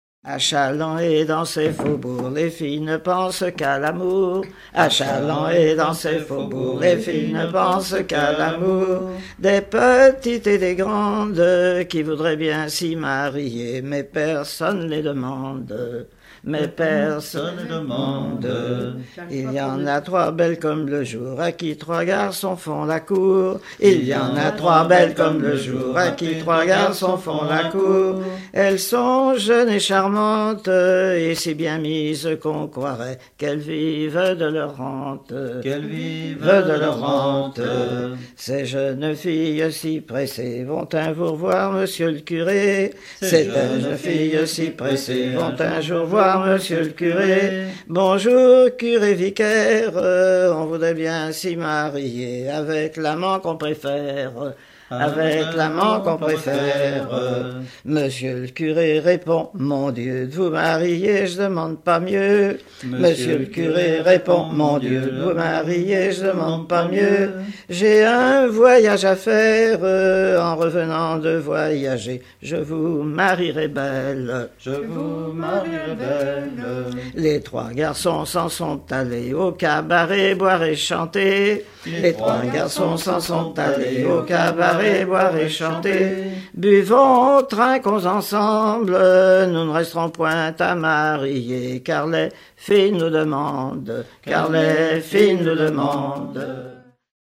Genre strophique